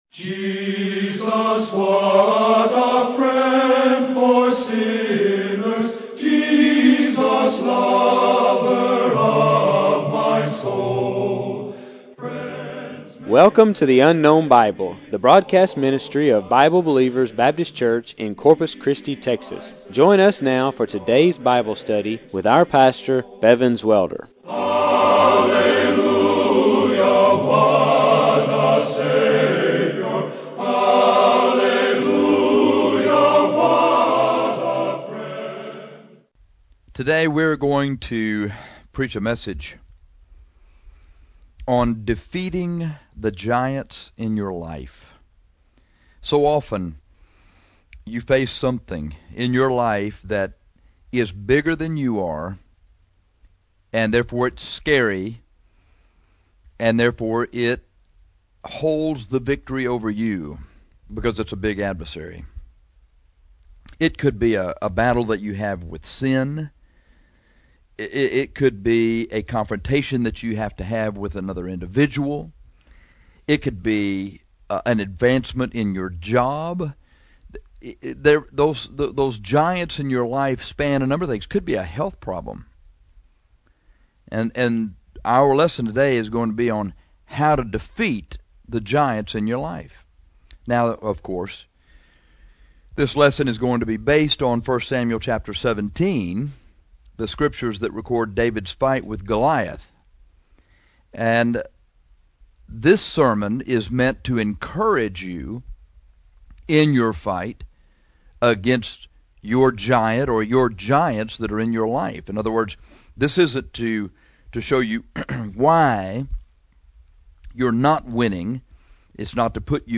This lesson on defeating the giants in your life is based upon the scriptures that record David’s fight with Goliath. This sermon is meant to encourage you in your fight against the giant or giants in your life.